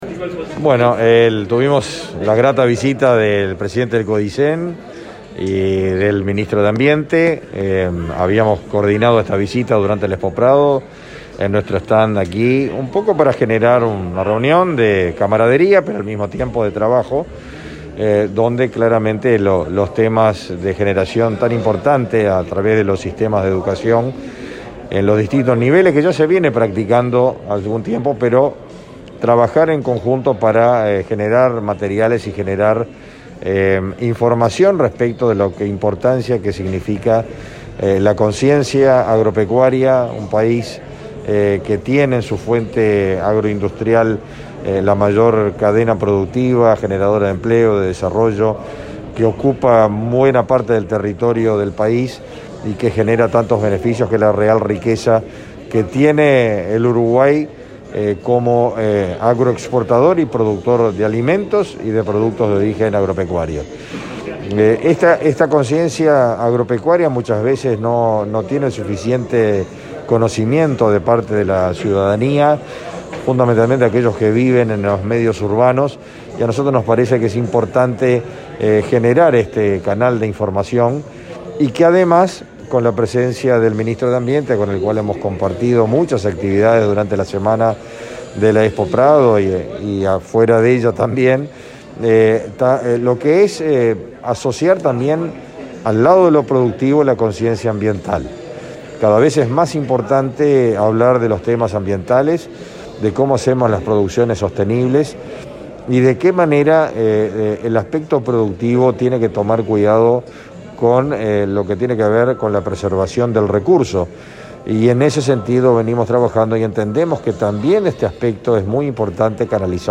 Declaraciones del ministro de Ganadería, Fernando Mattos
El ministro de Ganadería, Fernando Mattos, dialogó con Comunicación Presidencial acerca de la reunión sobre conciencia agropecuaria que mantuvo, este